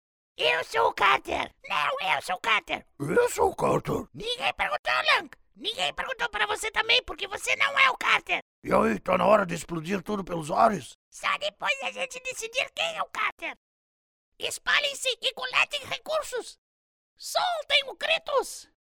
Male
Adult (30-50), Older Sound (50+)
Video Games
All our voice actors have professional broadcast quality recording studios.
57905Game_voices_-_Gnome_and_charger.mp3